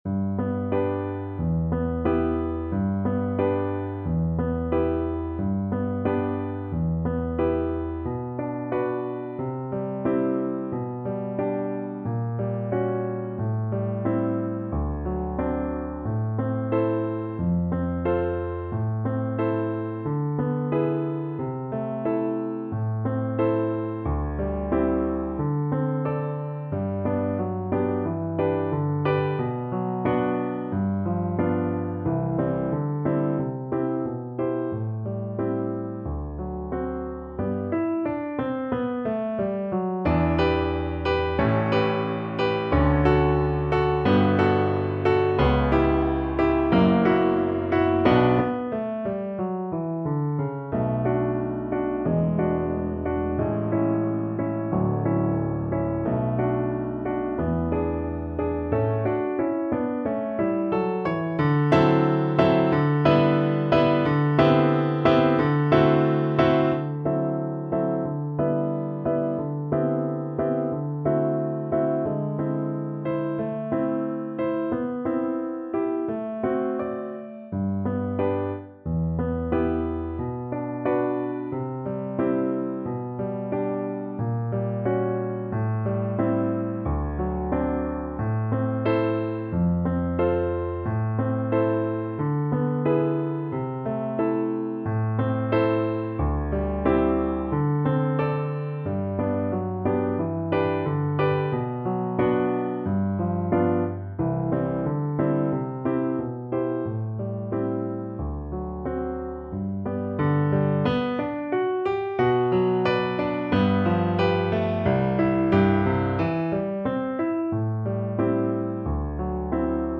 ~ = 100 Allegretto con moto =90
2/4 (View more 2/4 Music)